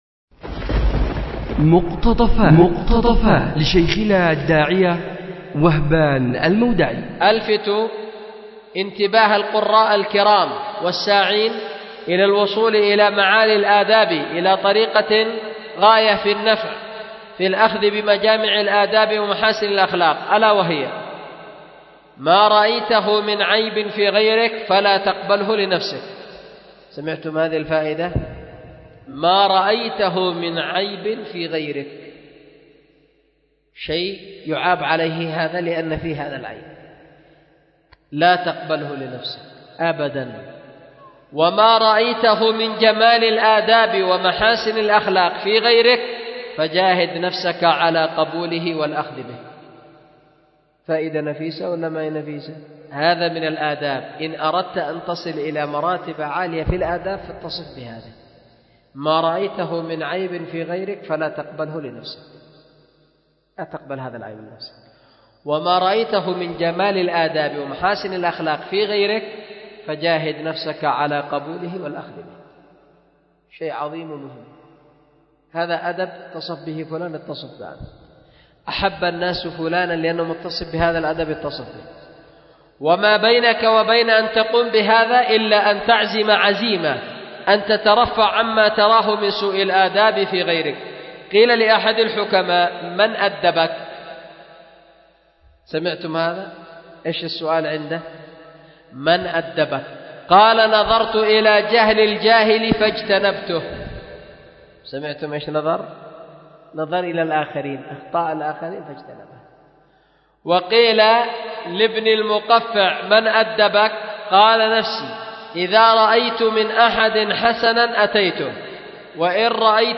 مقتطف من درس
أُلقي بدار الحديث للعلوم الشرعية بمسجد ذي النورين ـ اليمن ـ ذمار 1444هـ